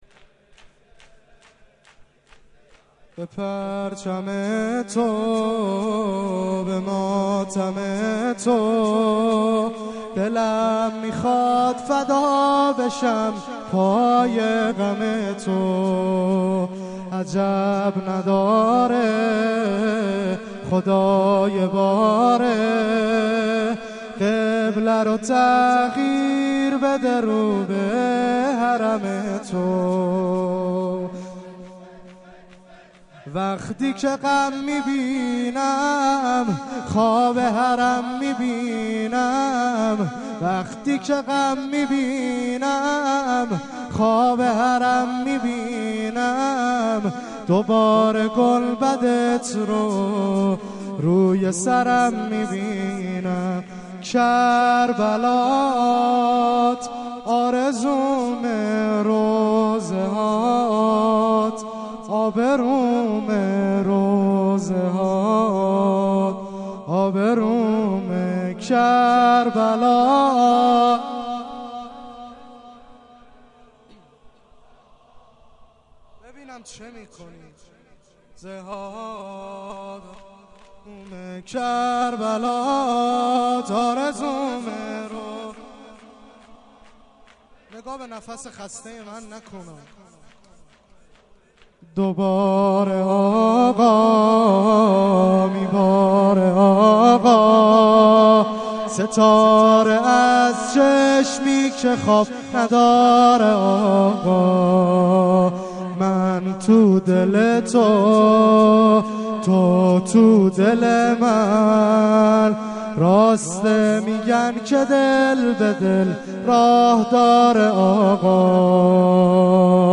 مراسم شب یازدهم دهه اول محرم الحرام ۱۴۳۷ ه.ق هیٔت محبان روح الله (ره)-1394/8/2
شور